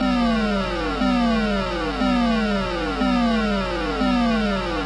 恼人的无人机2
描述：恼人的电子无人机噪音
Tag: 恼人的 无人驾驶飞机 外星人 视频游戏 空间 空间入侵者 复古